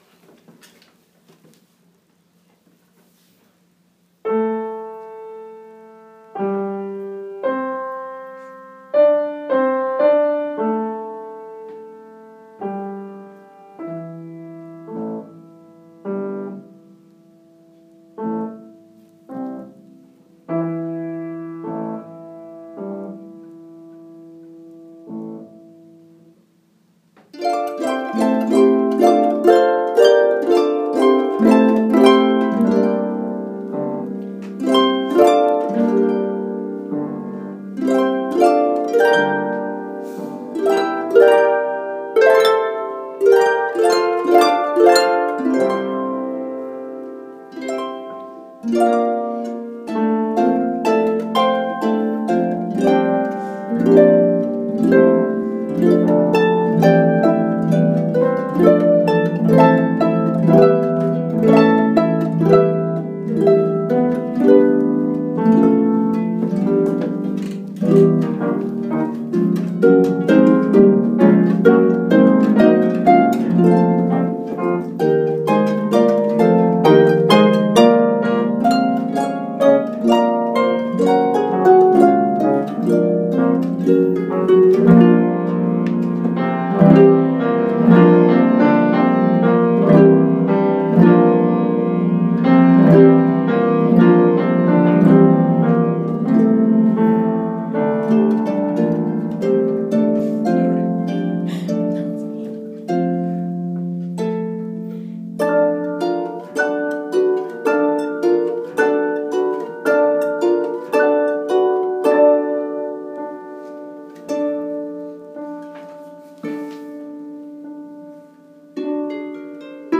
Debussy+with+Piano.m4a